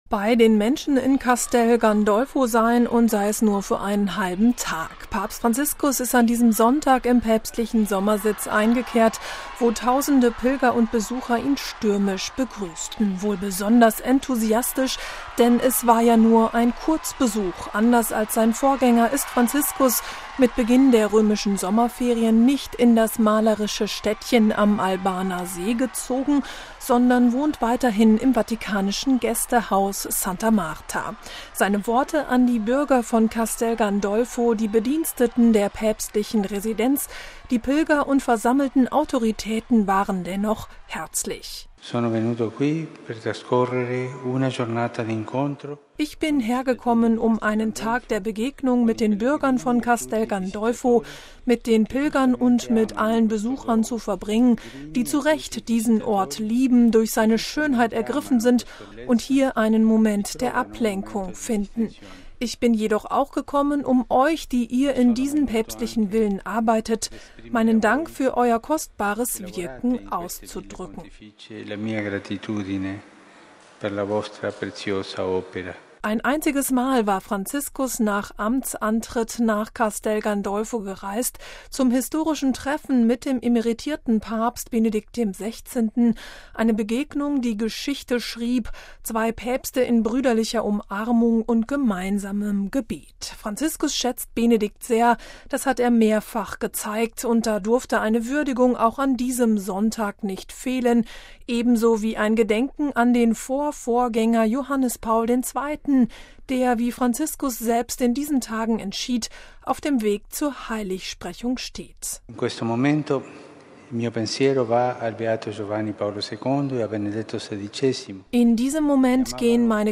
MP3 Bei den Menschen von Castel Gandolfo sein, und sei es nur für einen halben Tag – Papst Franziskus ist an diesem Sonntag im päpstlichen Sommersitz eingekehrt, wo tausende Pilger und Besucher ihn stürmisch begrüßten.
Der Bischof von Albano, Marcello Semeraro, inspirierte sich in seinem Grußwort an der Idylle des Ortes, um dem Papst die Zuneigung der gesamten Diözese zu zeigen: